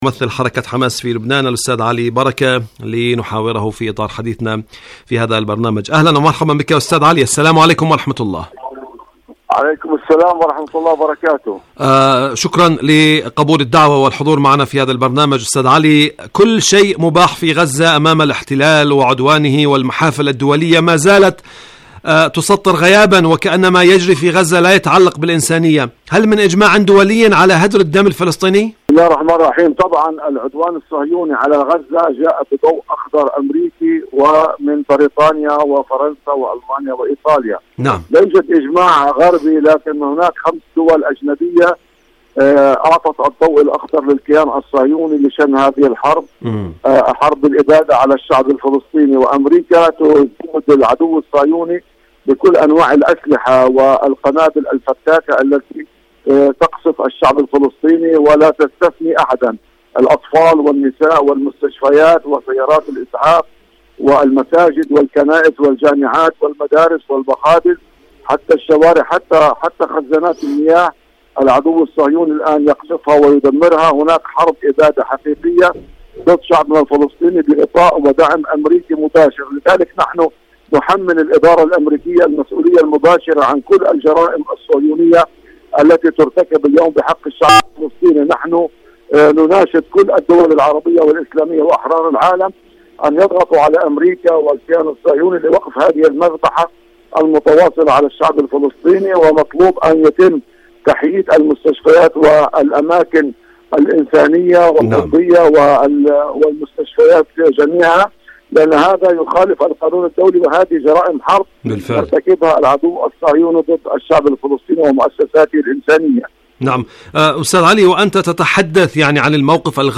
جراح غزة تحاصر العالم.. مقابلة